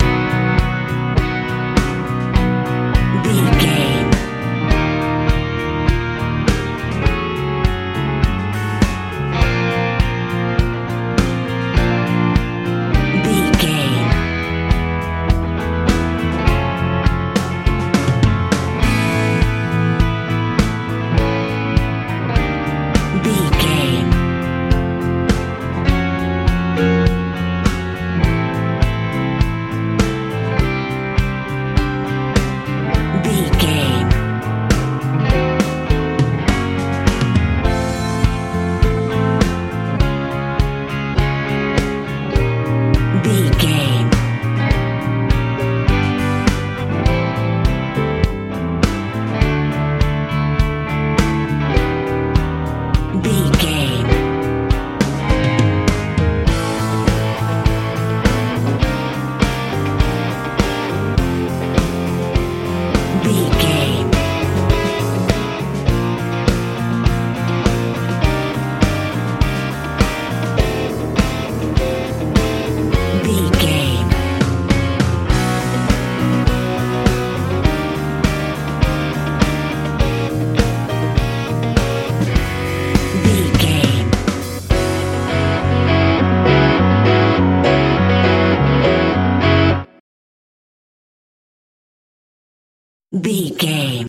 power ballad feel
Ionian/Major
bass guitar
drums
piano
electric guitar
80s
90s